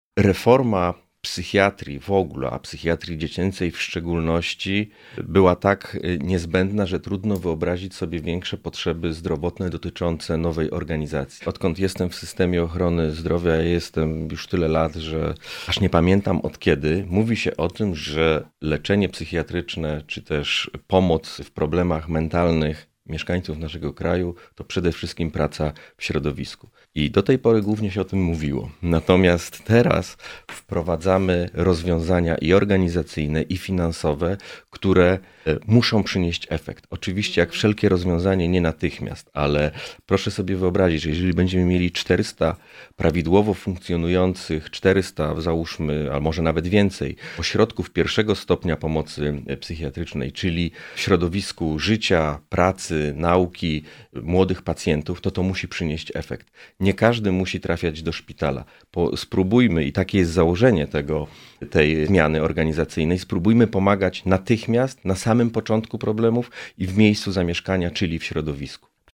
Mówi prezes NFZ – Filip Nowak.